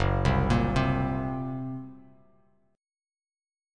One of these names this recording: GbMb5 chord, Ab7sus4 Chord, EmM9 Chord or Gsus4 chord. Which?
GbMb5 chord